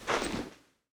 PixelPerfectionCE/assets/minecraft/sounds/item/armor/equip_leather3.ogg at mc116
equip_leather3.ogg